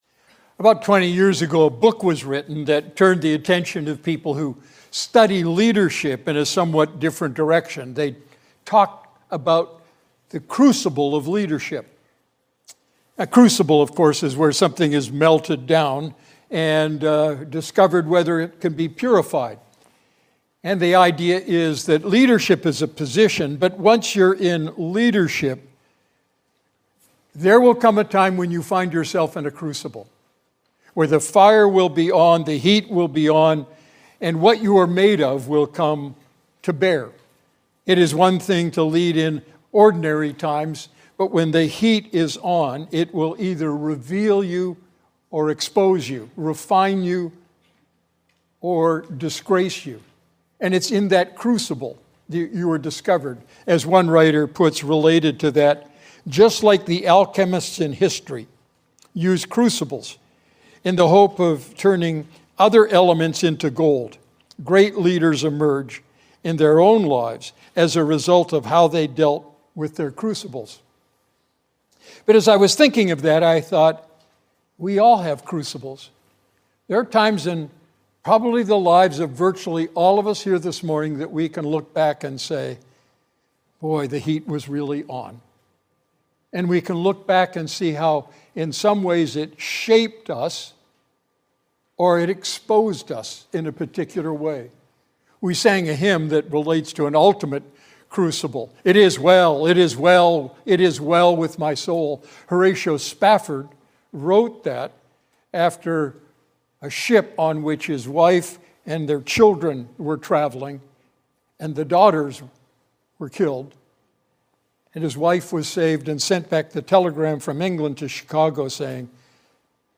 Sermon Archive | Redeemer Fellowship